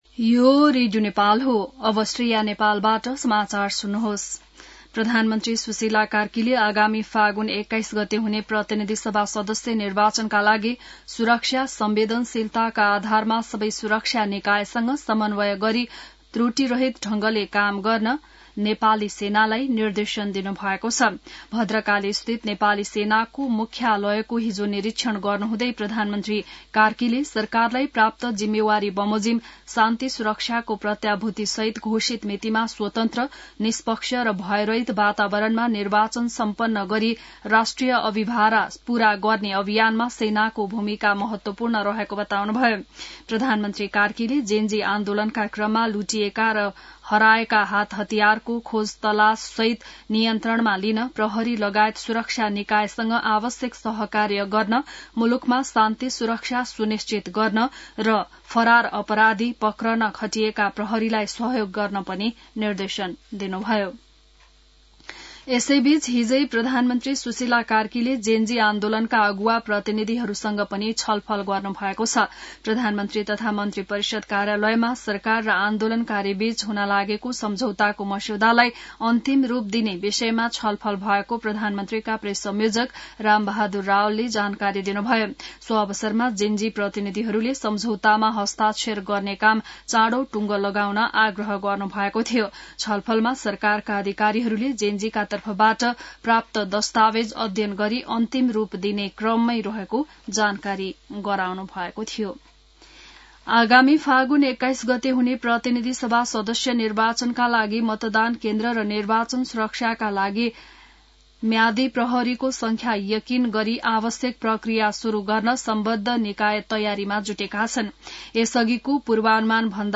बिहान ६ बजेको नेपाली समाचार : १३ मंसिर , २०८२